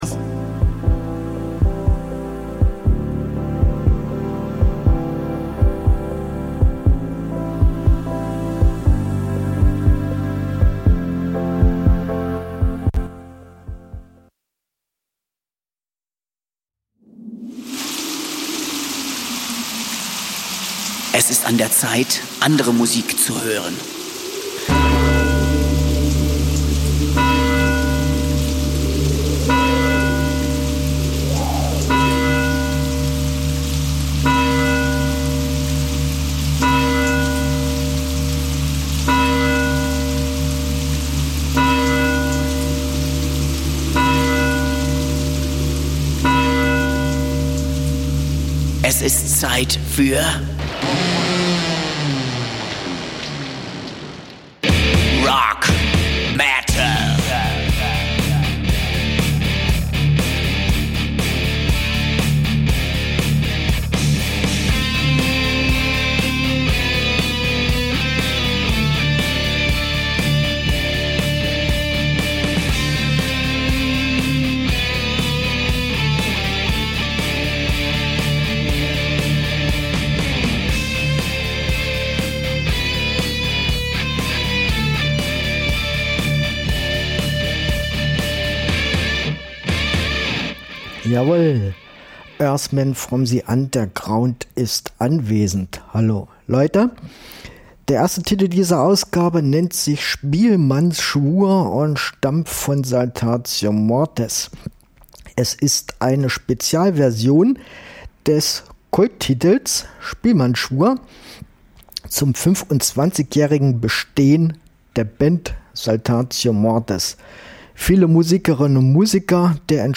Musik von Rock bis Metal!